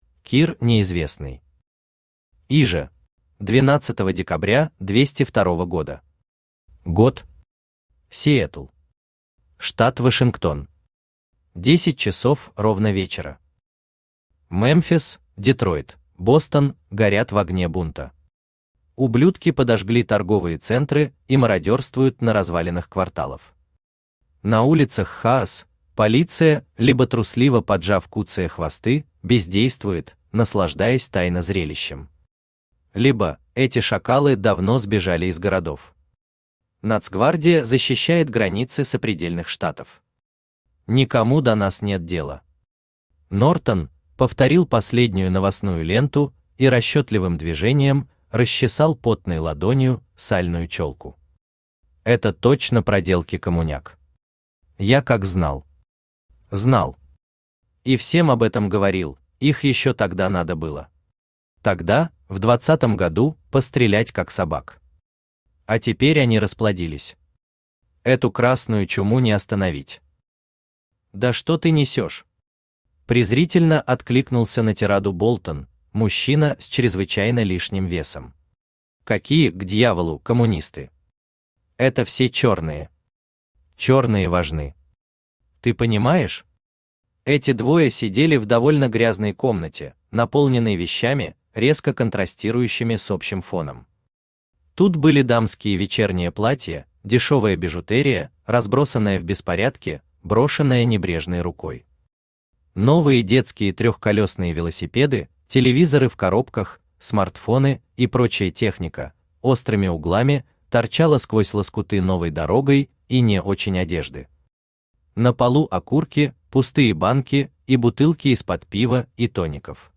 Aудиокнига Иже Автор Кир Николаевич Неизвестный Читает аудиокнигу Авточтец ЛитРес.